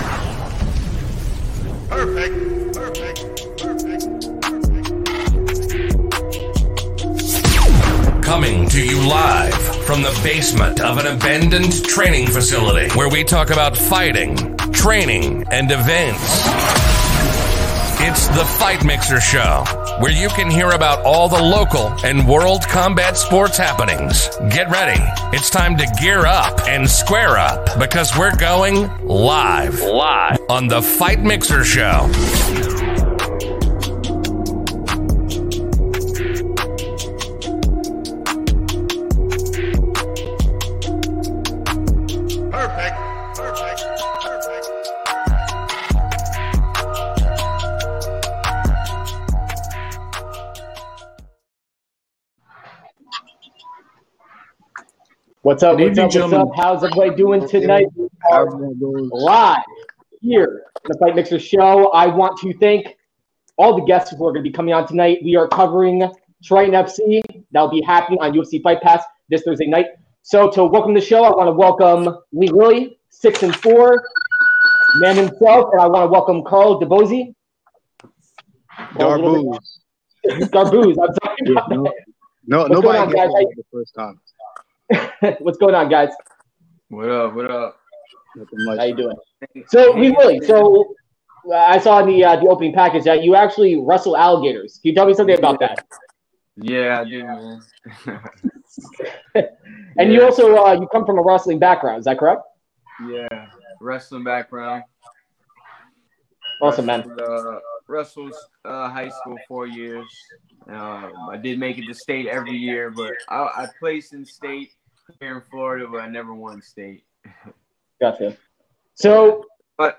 Titan FC 62 Fighter Interviews